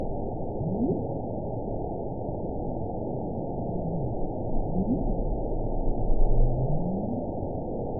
event 922021 date 12/25/24 time 10:21:26 GMT (5 months, 3 weeks ago) score 9.28 location TSS-AB10 detected by nrw target species NRW annotations +NRW Spectrogram: Frequency (kHz) vs. Time (s) audio not available .wav